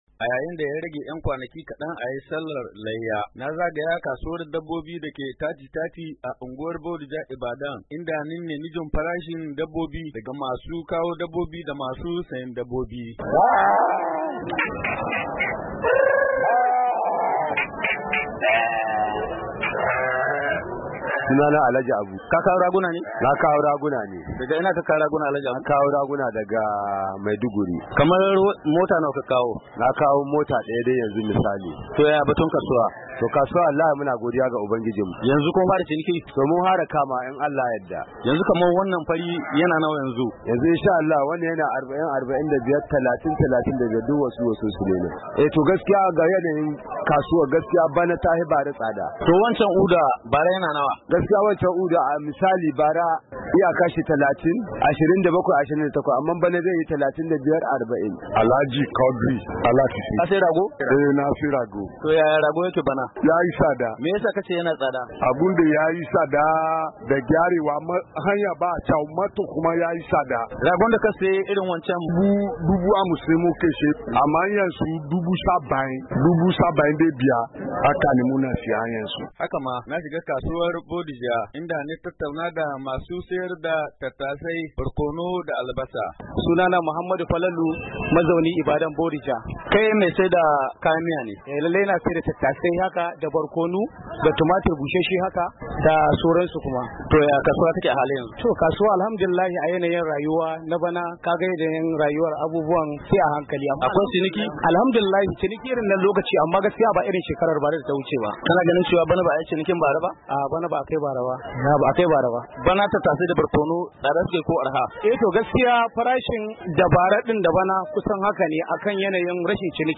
Muryar Amurka ta gudanar da bincike a kasuwar dabbobi ta 3030 a unguwar Bodija Ibadan, inda ta zanta da wasu masu sayar da dabbobin da kuma masu kai dabbobin kasuwar.